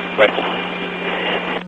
RifleB.ogg